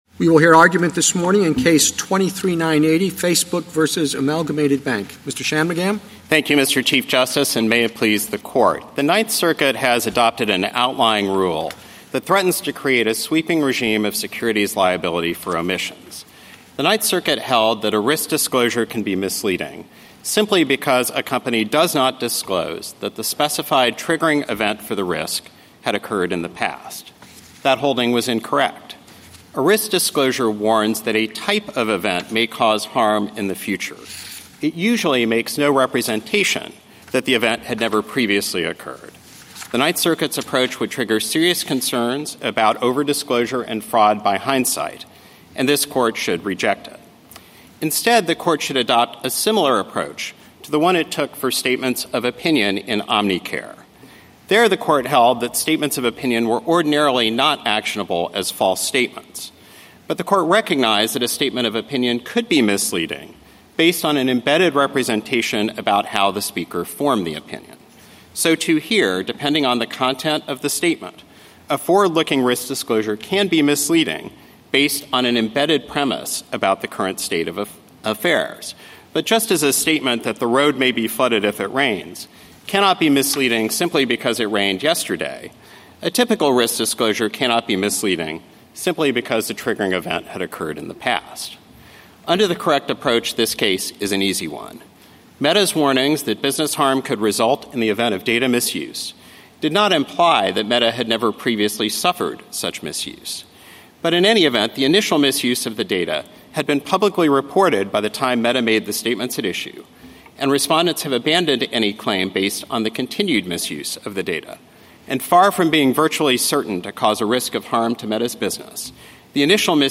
Oral Argument - Audio